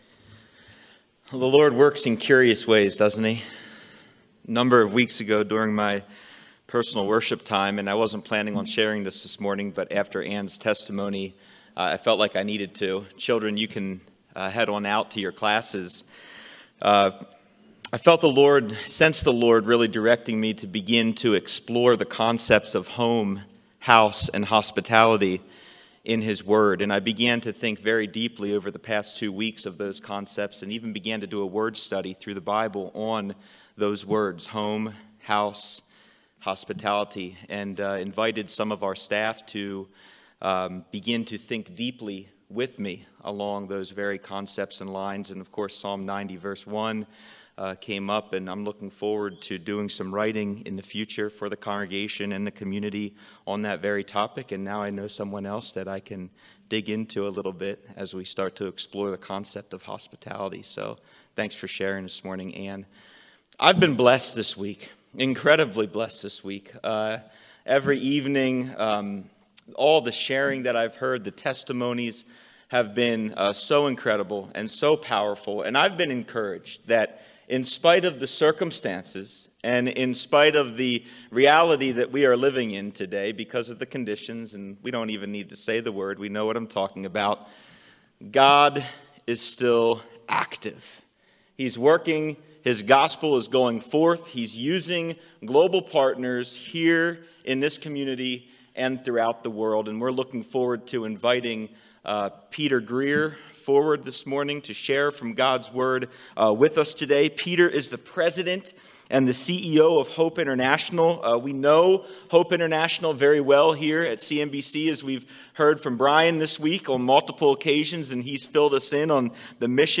Sermons | Calvary Monument Bible Church
2021 Global Outreach Conference